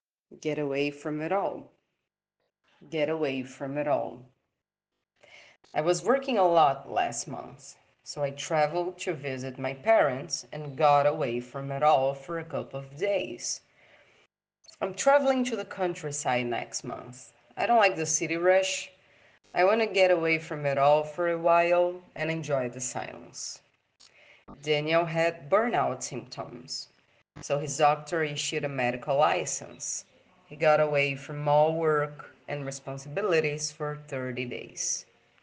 PRONUNCIATION 🗣